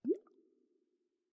bubbles2.ogg